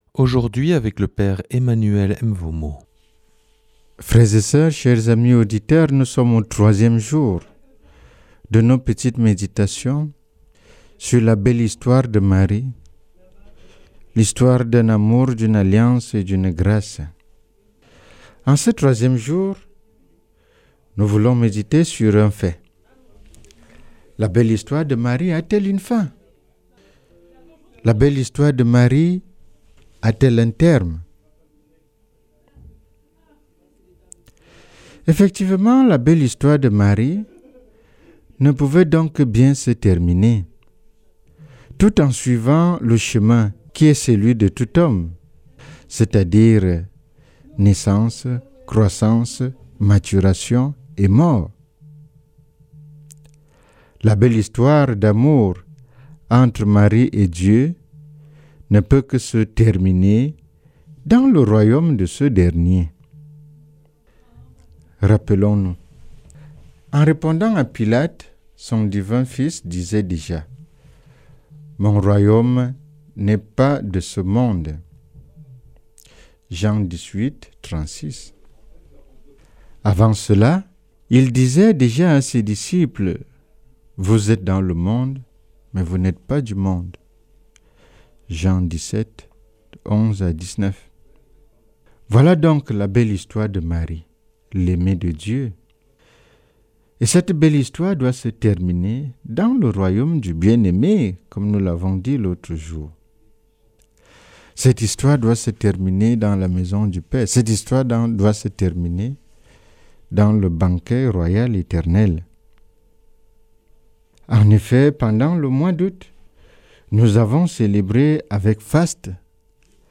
Enseignement Marial du 04 sept.